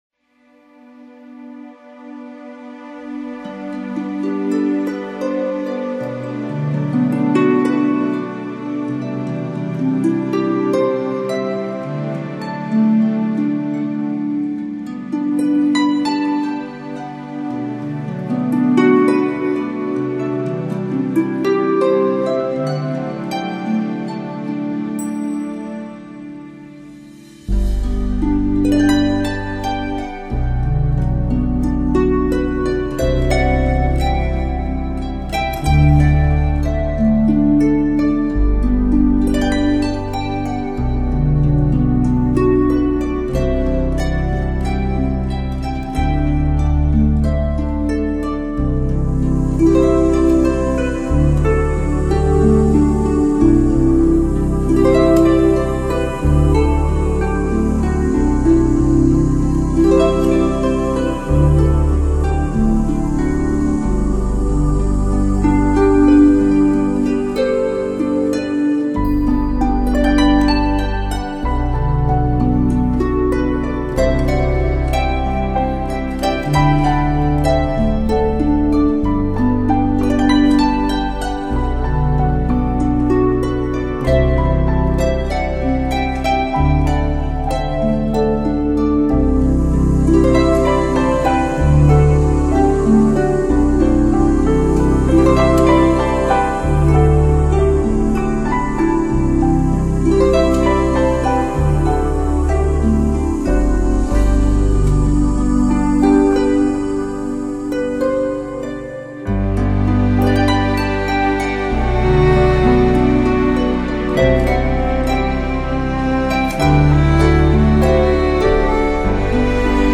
他的曲风优雅而冷冽，高贵而清澈。
温柔的竖琴，并配以小提琴，键盘和吉他弹奏出的美秒曲调在你的内心深处荡漾，非常的写意自然